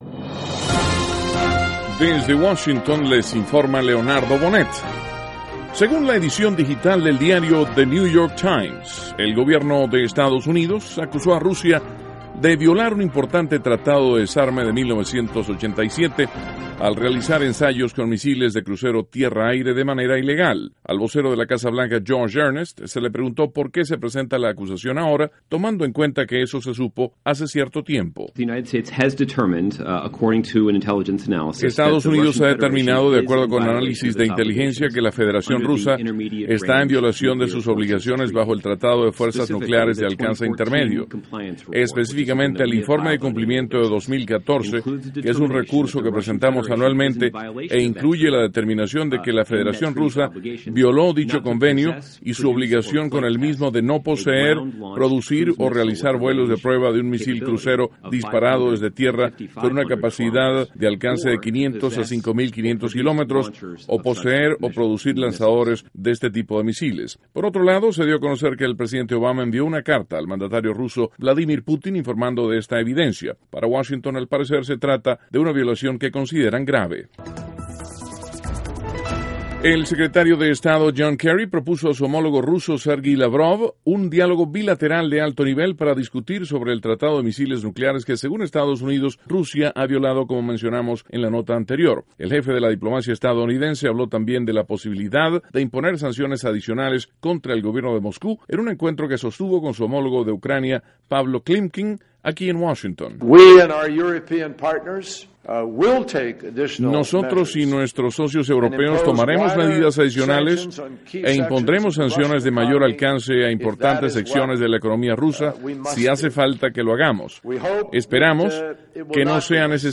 Duración: 3:18 1.- Gobierno de Estados Unidos acusa a Rusia de violar Tratado de Misiles de 1987. (Sonido – Earnest – Casa Blanca) 2.- Secretario de Estado Kerry habla de la imposición de más sanciones contra Rusia. (Sonido Kerry) 3.- Según Director General de Salud de Estados Unidos, casos de cáncer de piel han aumentado 200 por ciento desde 1973.